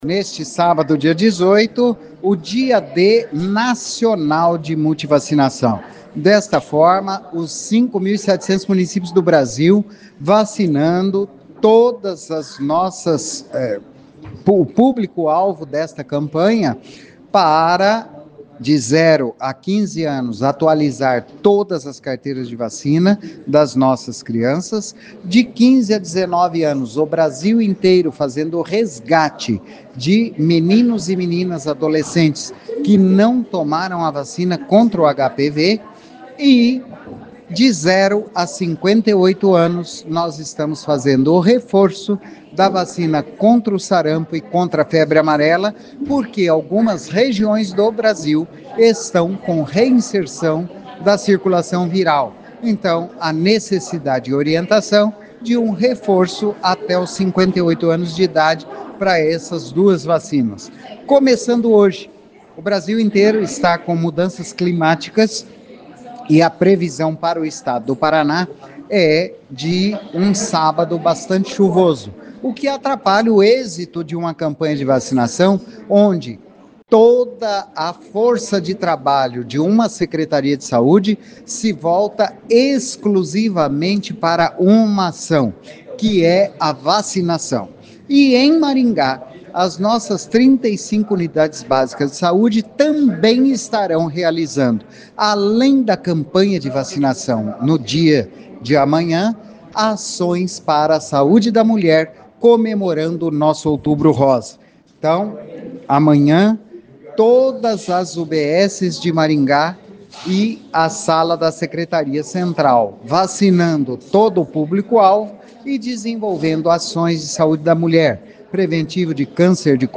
O secretário de Saúde Antônio Carlos Nardi reforça que amanhã todas as 35 unidades de saúde estarão abertas.